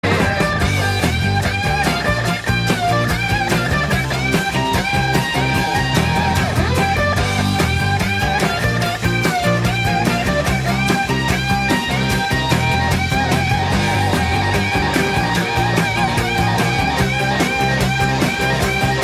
high-energy audio clip